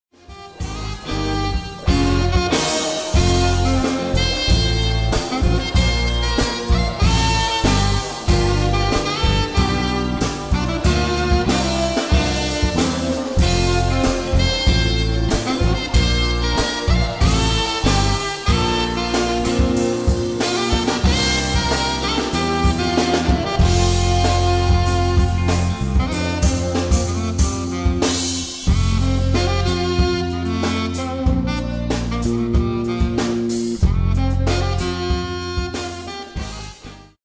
all saxophones
keyboards, programming, trumpet
bass, rhythm guitar
drums
flute
vocals
percussion